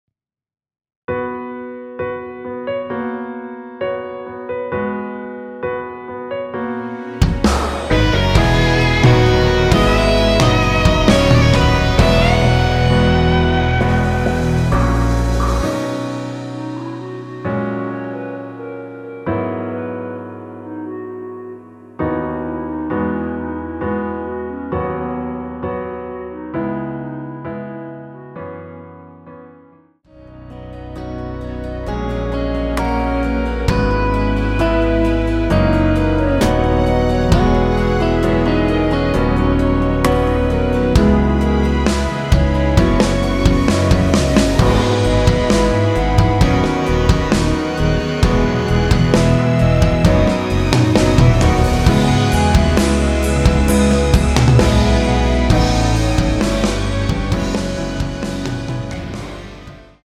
원키에서(-2)내린 (1절앞+후렴)으로 진행되는멜로디 포함된 MR입니다.(미리듣기 확인)
멜로디 MR이란
앞부분30초, 뒷부분30초씩 편집해서 올려 드리고 있습니다.
중간에 음이 끈어지고 다시 나오는 이유는